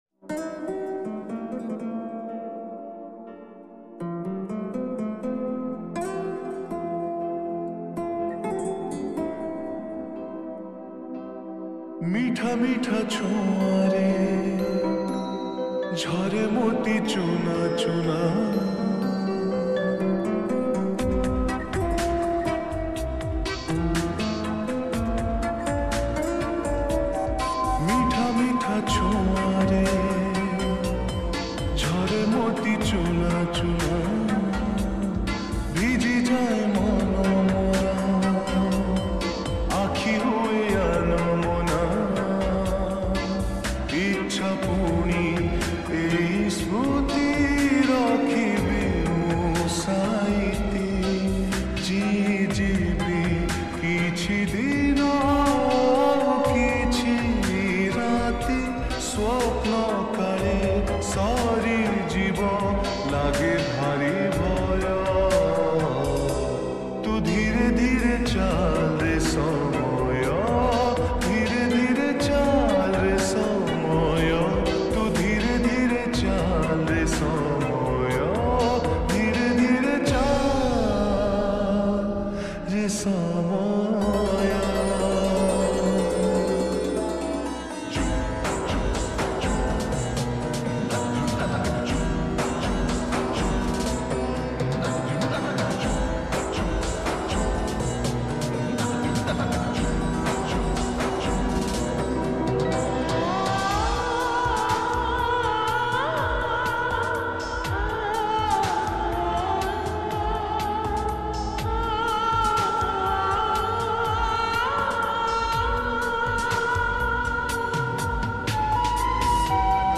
odia lofi song Songs Download